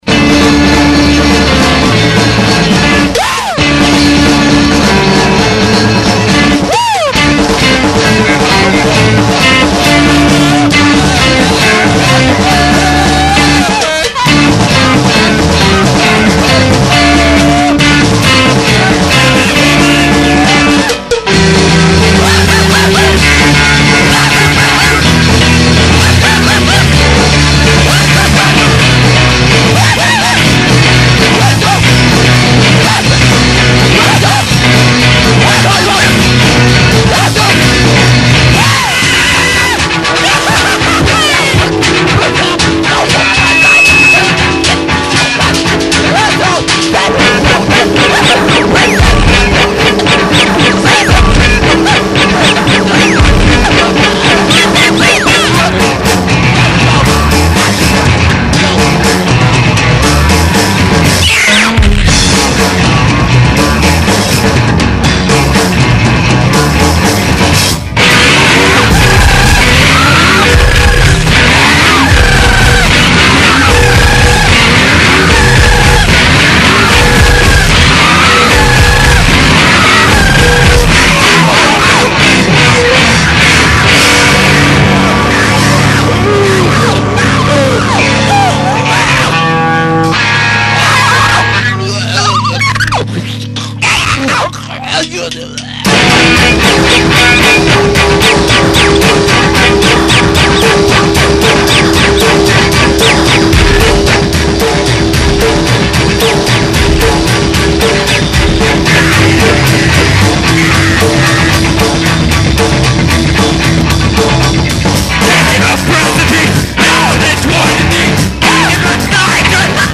vocals, samples
bass, guitar, keyboards
drums
Like a carnival inside a tornado.